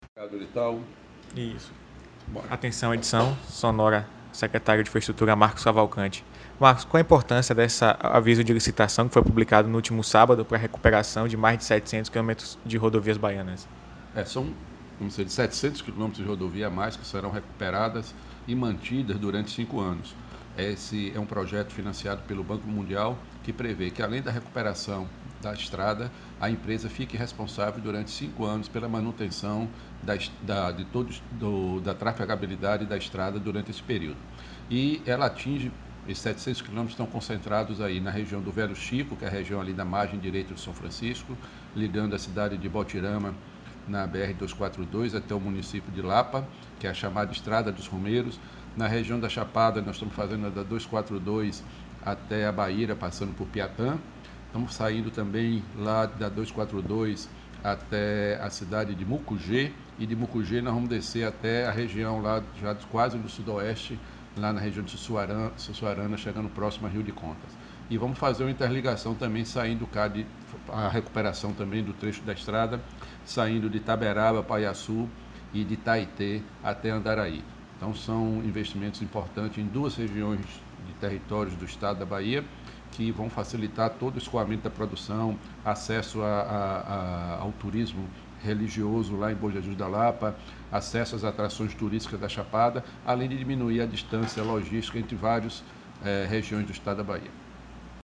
Áudio secretário Marcus Cavalcanti: